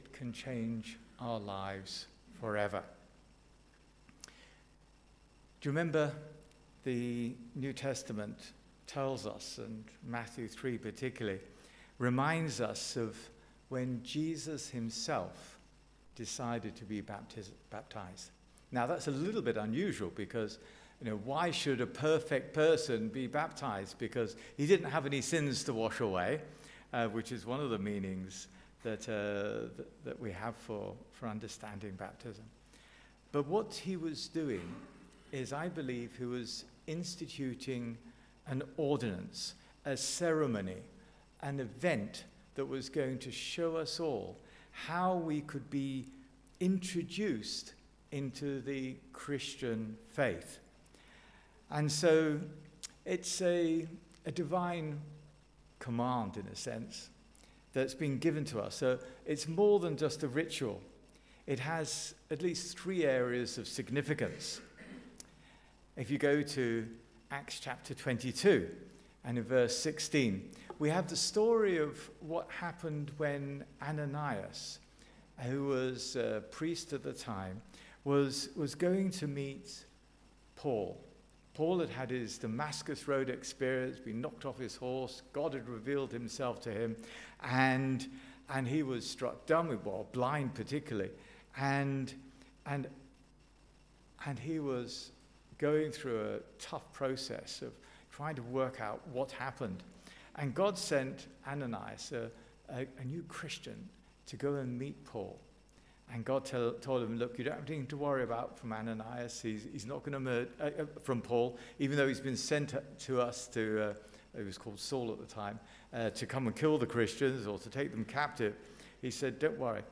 Baptism Service